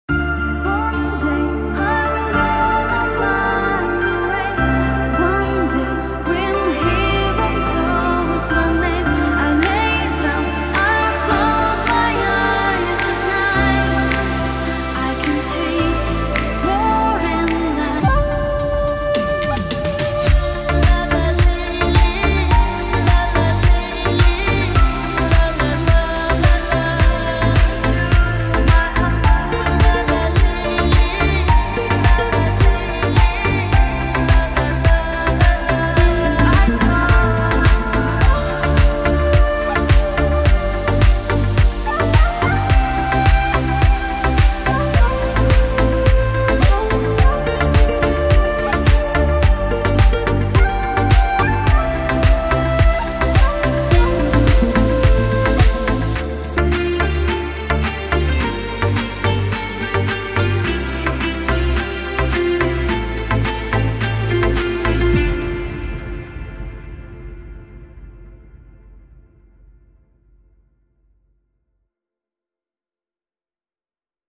• Качество: 320, Stereo
remix
deep house
спокойные
нарастающие
нежные